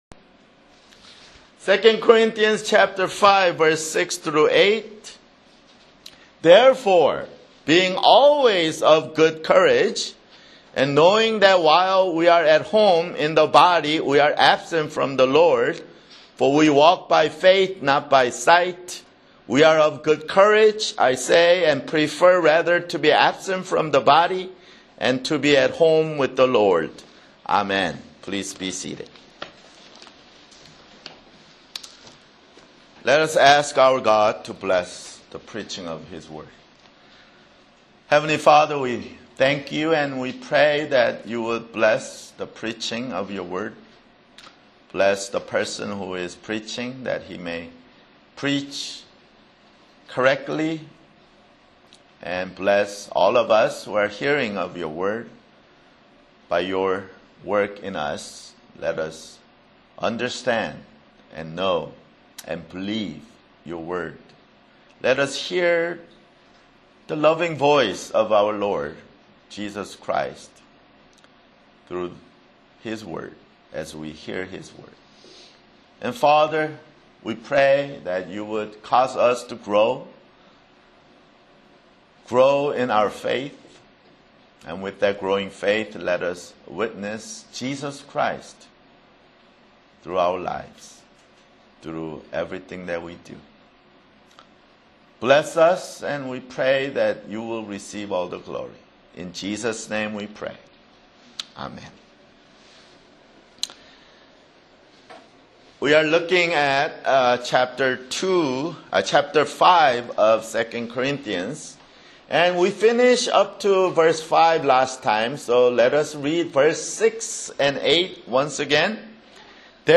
Sunday, September 23, 2012 [Sermon] 2 Corinthians (26) 2 Corinthians 5:6-8